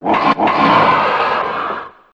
One of Bowser's voice clips in Mario Kart 64